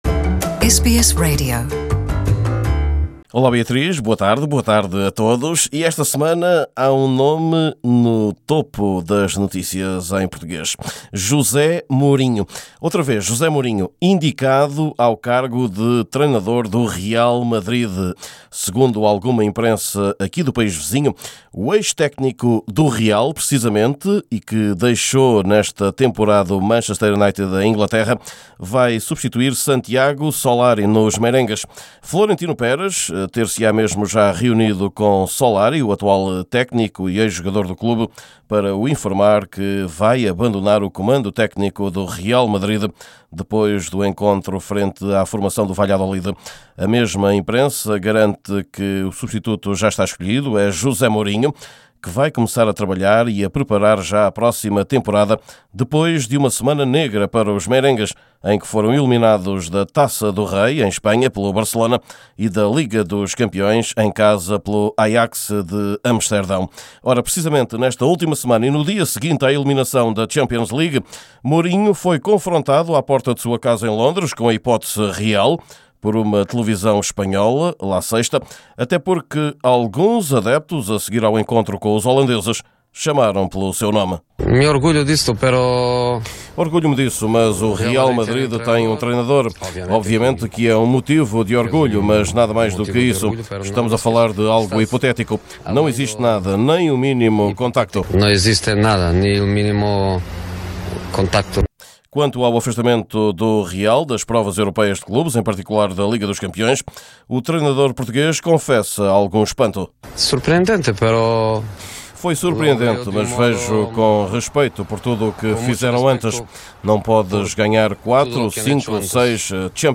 Neste seu boletim semanal